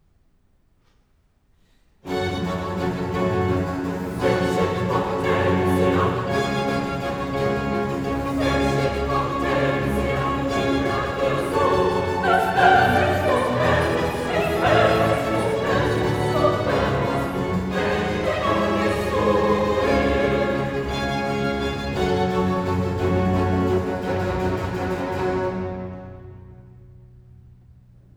Aufnahmen aus dem 1. Mäzenatenkonzert (April 2018)